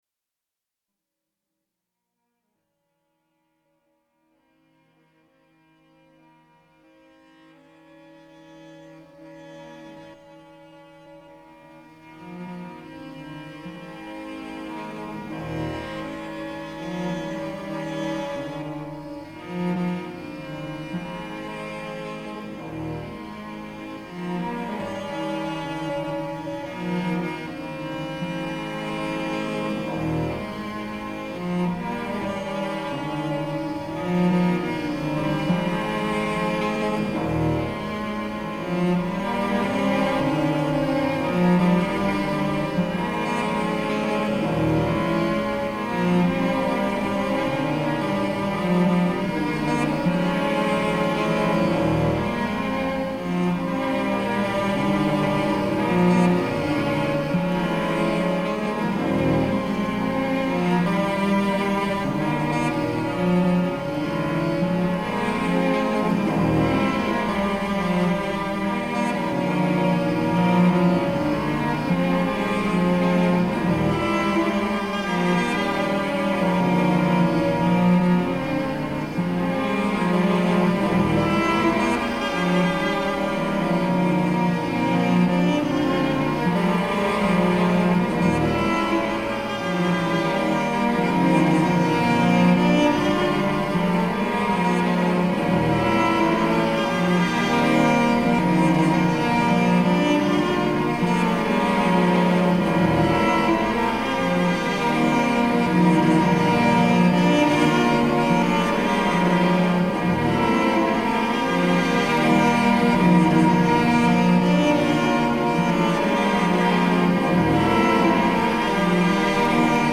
Minimal-Cellos_1.mp3